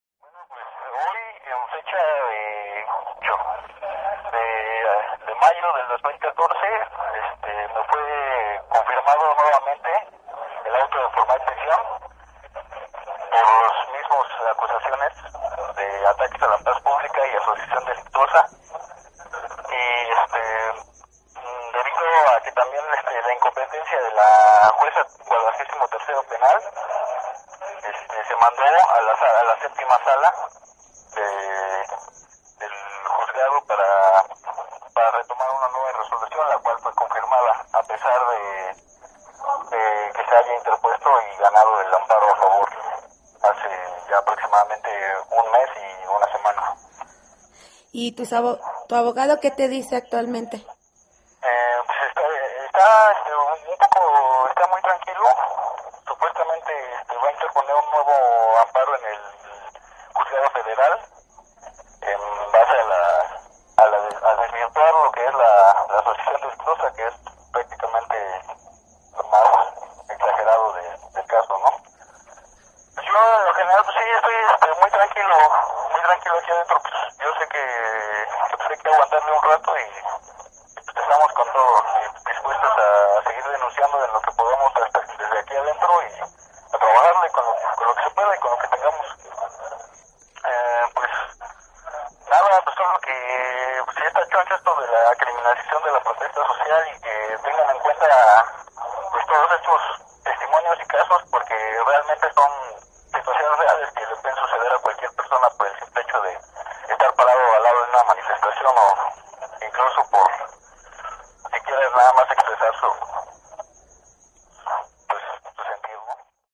Entrevista_ratifican_auto.mp3